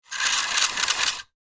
Throttle Controller Rope, Pulling Fast 3 Sound Effect Download | Gfx Sounds
Throttle-controller-rope-pulling-fast-3.mp3